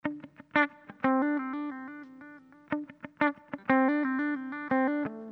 Sons et loops gratuits de guitares rythmiques 100bpm
Guitare rythmique 64